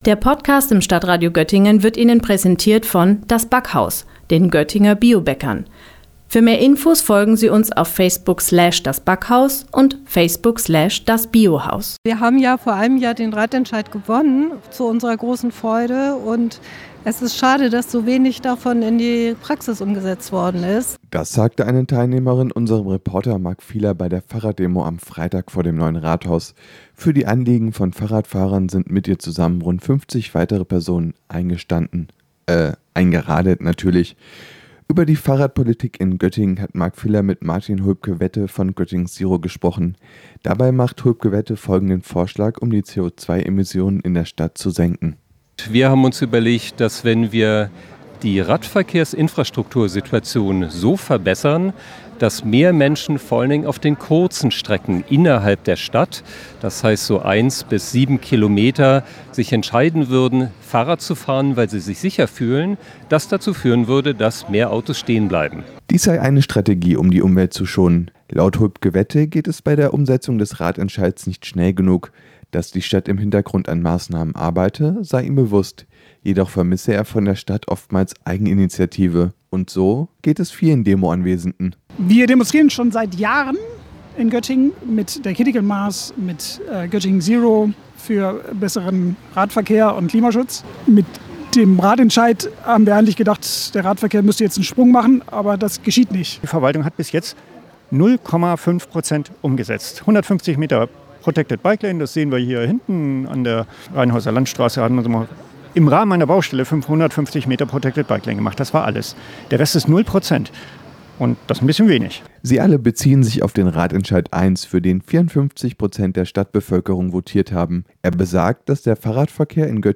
Er hat mit den Teilnehmenden gesprochen.
Fahrraddemo_podcast-playout.mp3